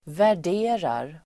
Uttal: [vär_d'e:rar]